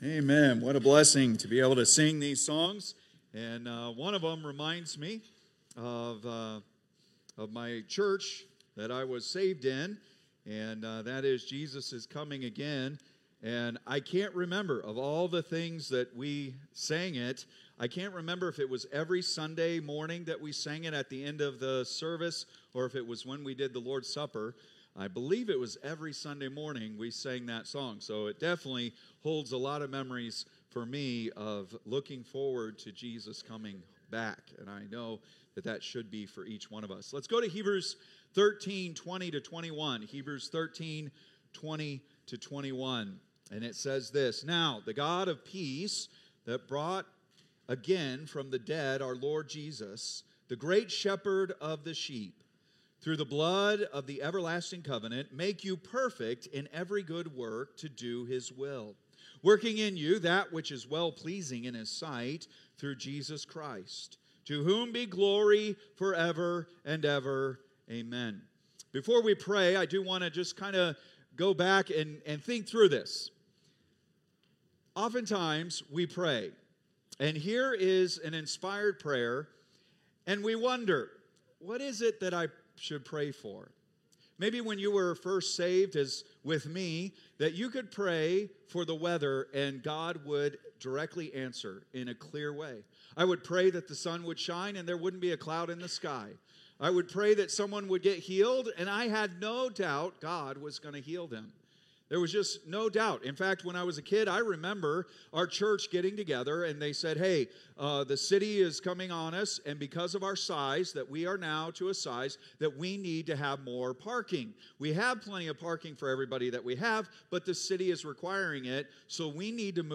Date: March 23, 2025 (Sunday Evening)